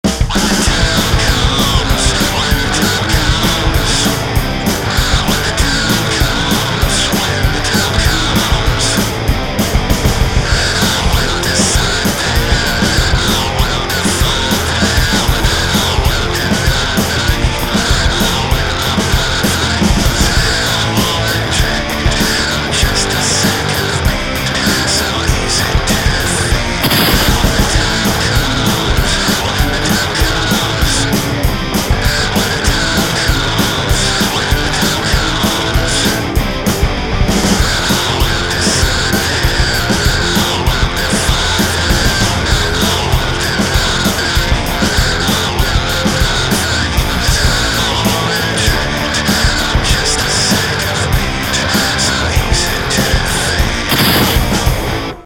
Use of the sound of gunfire
I wish you didn’t distort your vocals so much.
Nice riffs! Good punk length and ending!
I don't mind distorted vocals, but ya still gotta hear'em.